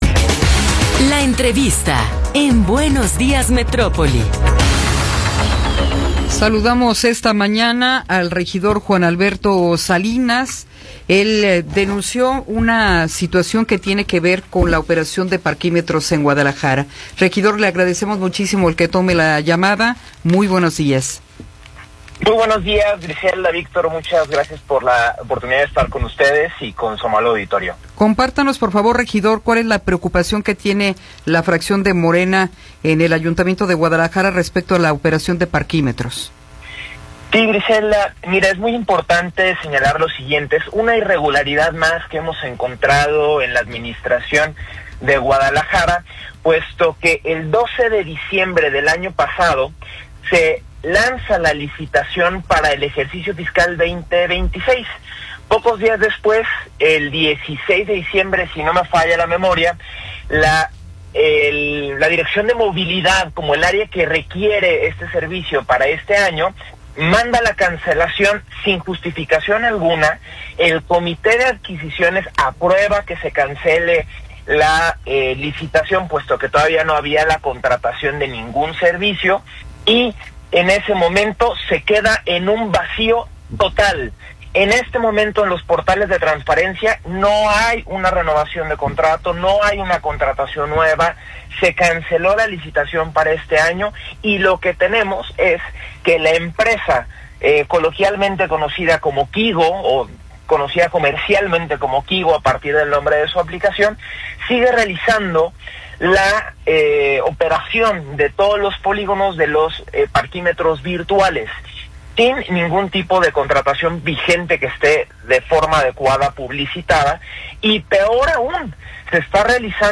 Entrevista con Juan Alberto Salinas Macías